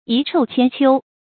遺臭千秋 注音： ㄧˊ ㄔㄡˋ ㄑㄧㄢ ㄑㄧㄡ 讀音讀法： 意思解釋： 同「遺臭萬載」。